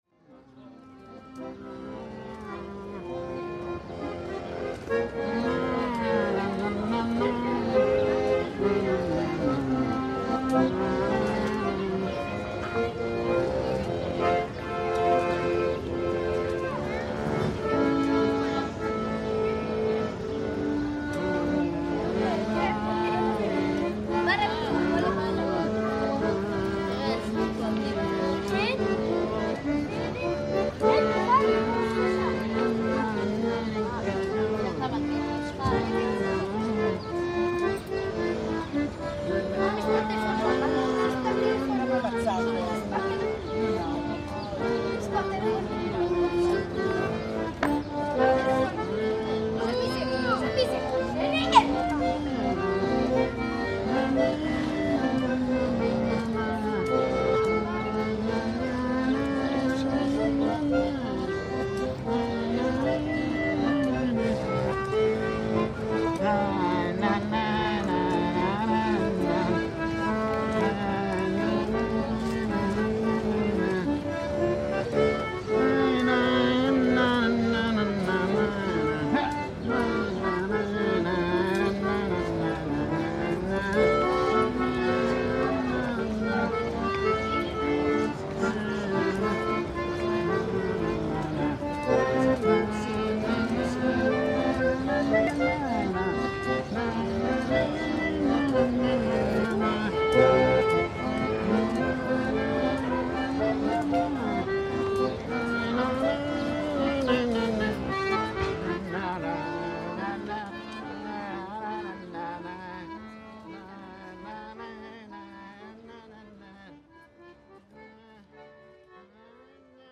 an old man playing his accordion in the Armenian quarter of Jerusalem. The Armenian presence in Jerusalem dates back to the 4th century AD, coinciding with Armenia's adoption of Christianity as its national religion, leading to the settlement of Armenian monks in the city.